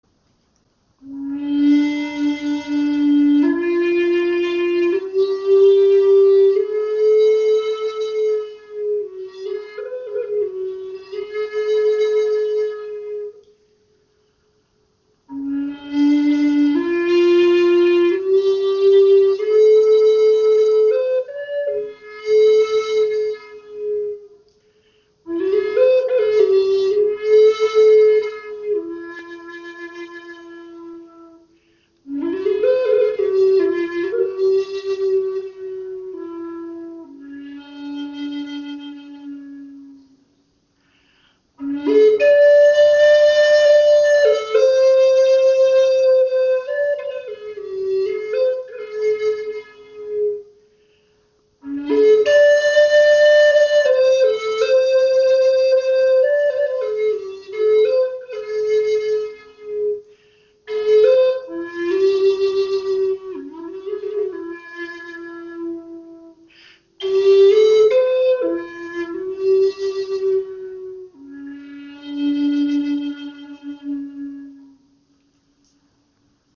Klangbeispiel
Diese Gebetsflöte in D ist auf 432 Hz gestimmt.